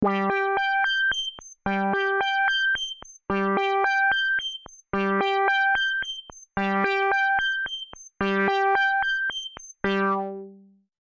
标签： MIDI-速度-89 FSharp4 MIDI音符-67 ELEKTRON - 模拟 - 四 合成器 单票据 多重采样
声道立体声